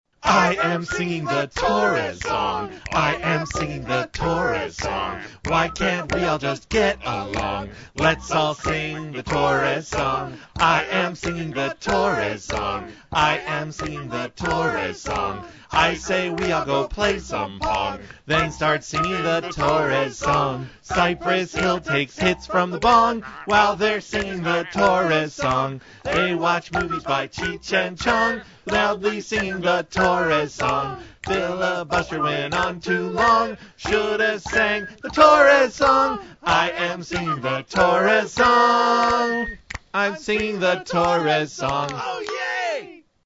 They're in mp3 format, and the quality is, uh, not excellent.